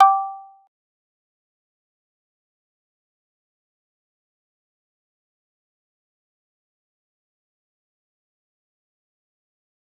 G_Kalimba-G5-pp.wav